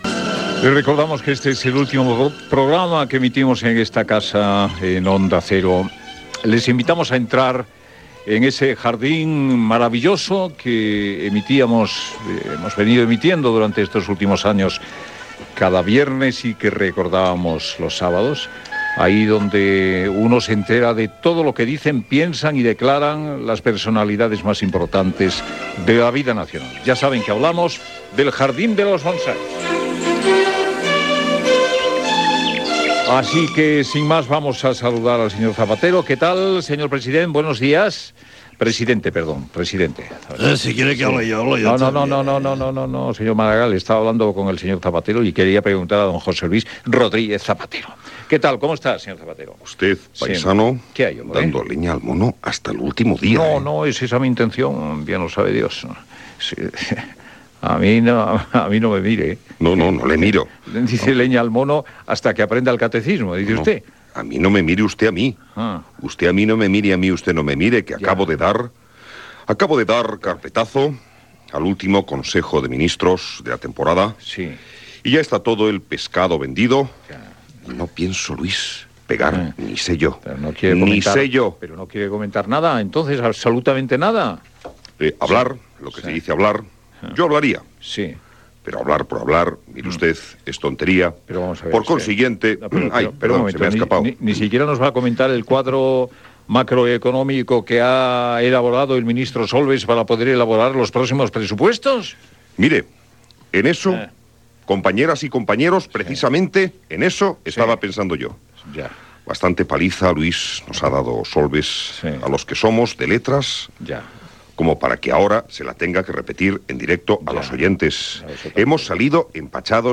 Fragment de la última emissió de la secció "El jardín de los bonsais" dins de "Protagonistas" a Onda Cero. Imitació del president del govern espanyol José Luis Rodríguez Zapatero. Imitacions del president de la Generalitat Pasqual Maragall i del polític del Partido Popular José María Aznar.
Info-entreteniment